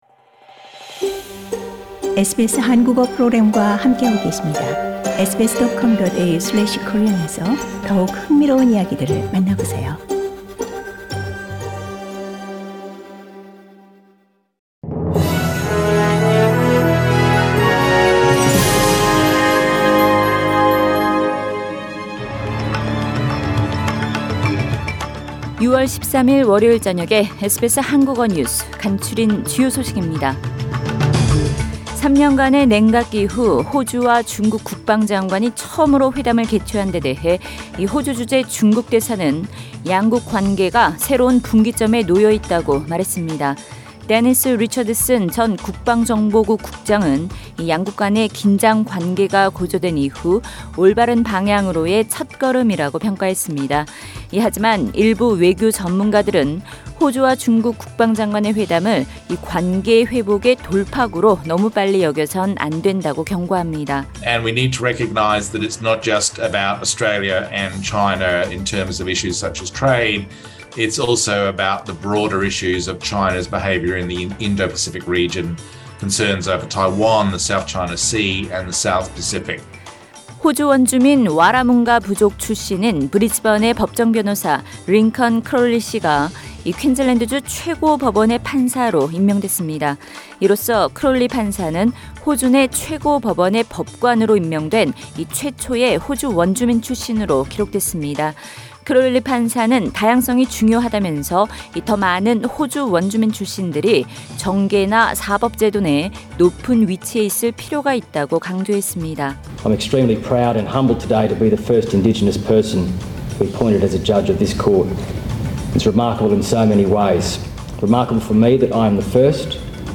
2022년 6월 13일 월요일 저녁 SBS 한국어 간추린 주요 뉴스입니다.